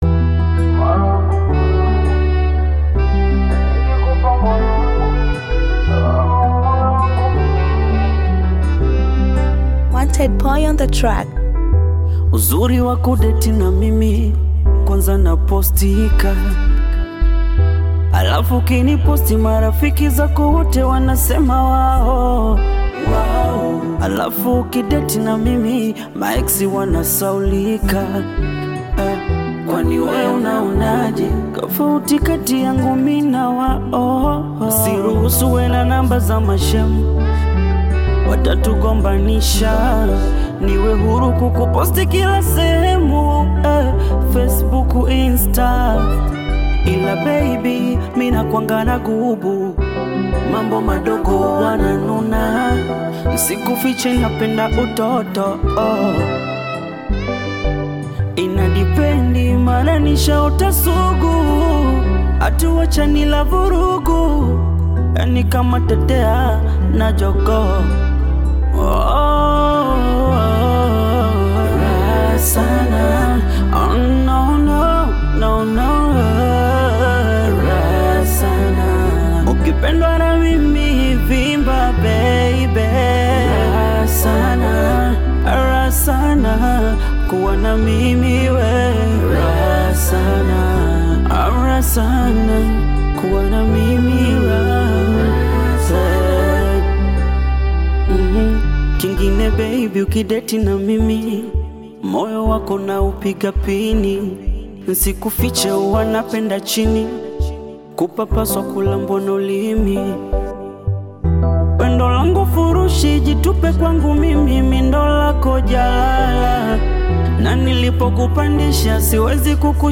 Tanzanian Bongo Flava artist, singer and songwriter
Bongo Flava You may also like